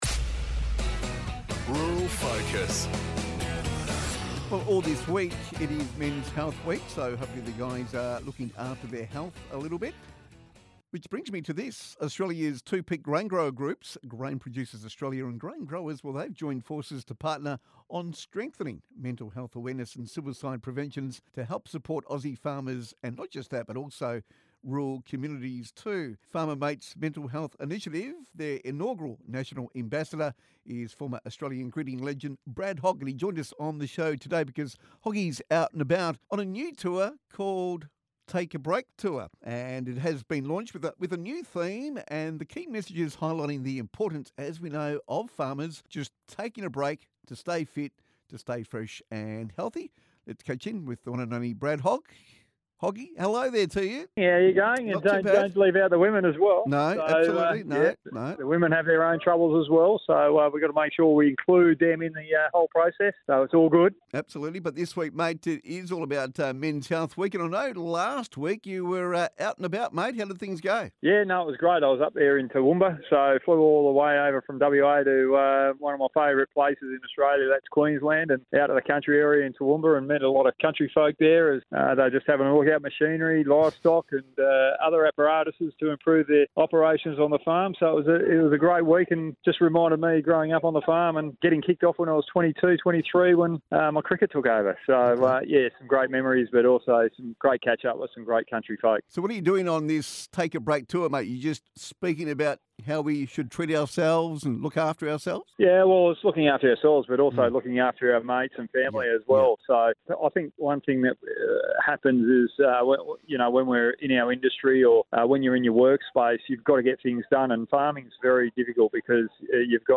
RURAL FOCUS | Brad Hogg Interview